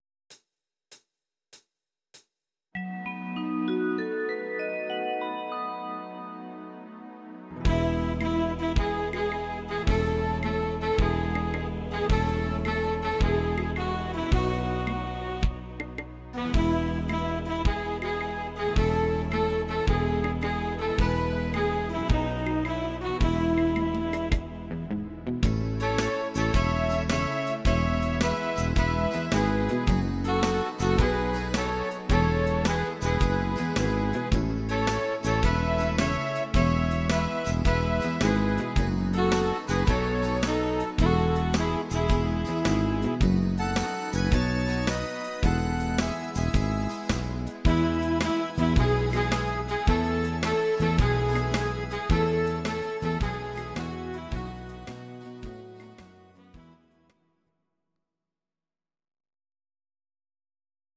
Audio Recordings based on Midi-files
Oldies, German, 1950s